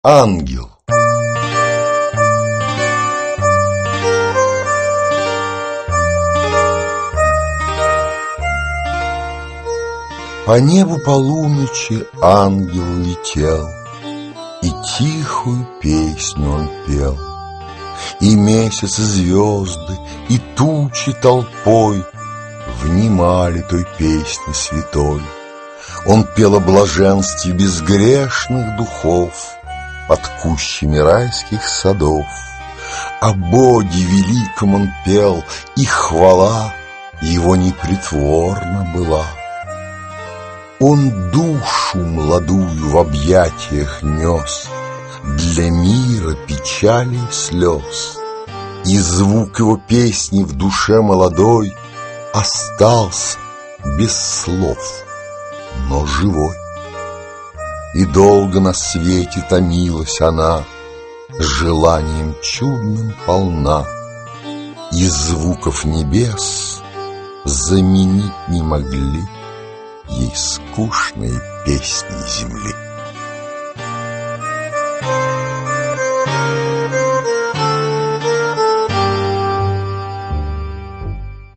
Аудиокнига Бородино. Стихи и поэмы | Библиотека аудиокниг